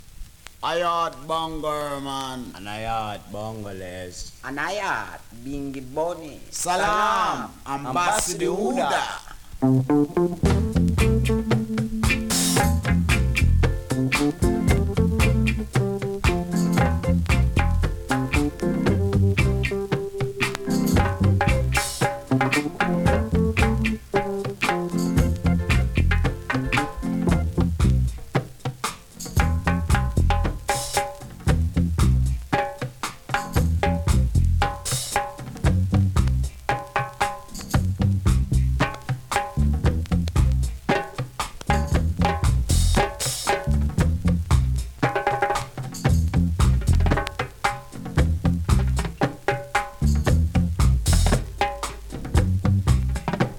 2020 NEW IN!!SKA〜REGGAE!!
スリキズ、ノイズかなり少なめの